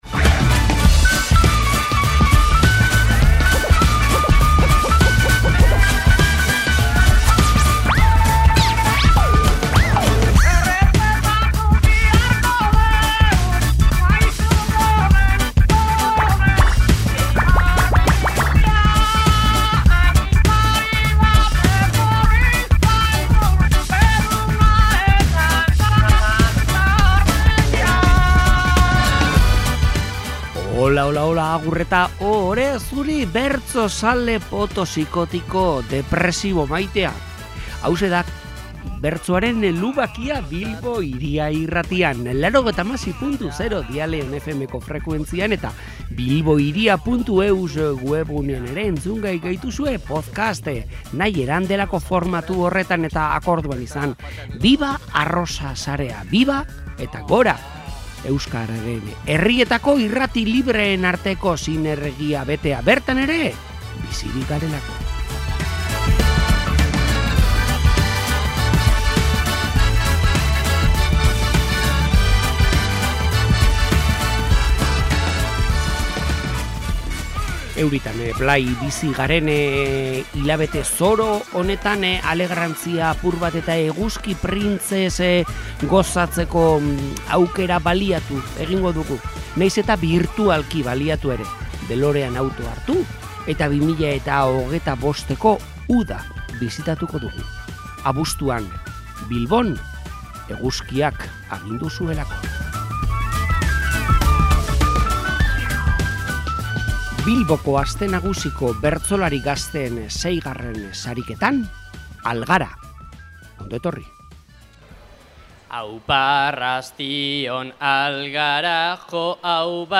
POTTO: Algara konpartsan, Bilboko Aste Nagusiko bertsolari gazteen VI. sariketa
Urteroko zita du bertsoak Algara konpartsan, eta 2025eko abuztuaren 19an, asteartearekin, bertso-eguna izan genuen ardatz.
Bertso-egunaren baitan, hainbat ekimen eta, besteren artean, Bilboko Aste Nagusiko bertsolari gazteen VI. sariketa.
Eguneko bertso-aukeraketa diktatoriala duzue entzungai